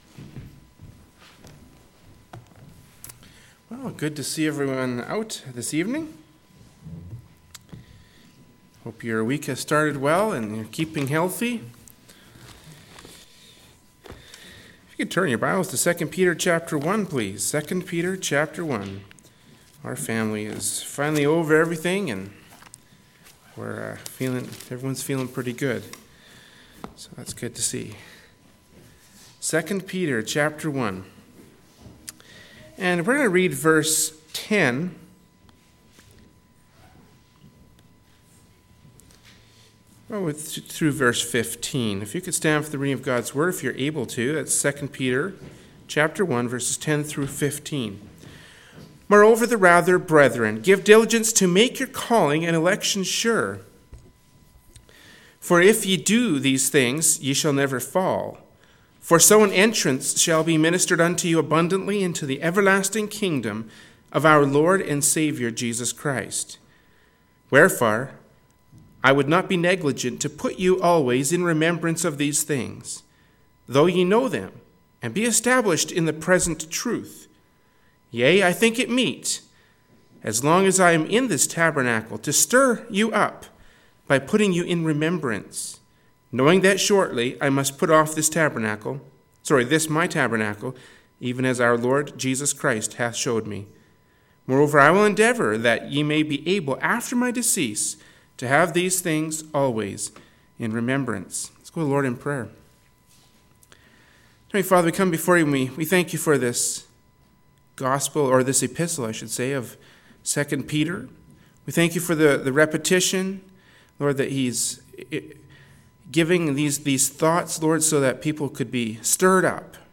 Service Type: Wednesday Evening Service